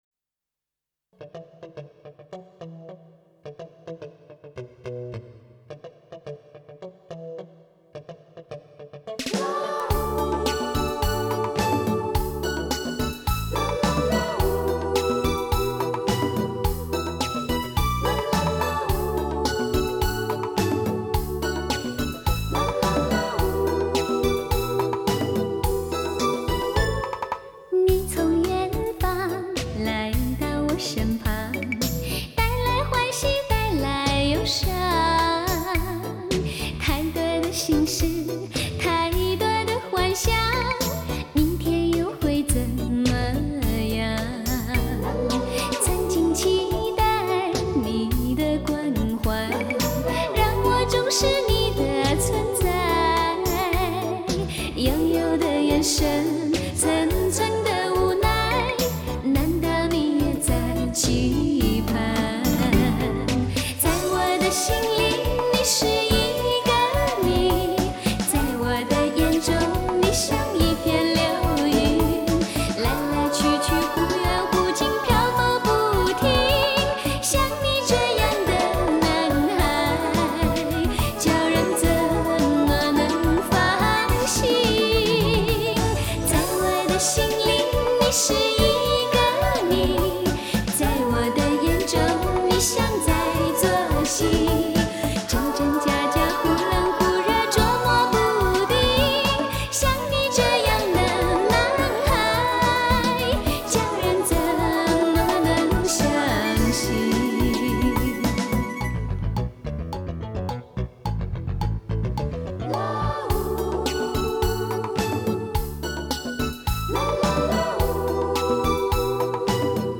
Жанр: Chinese pop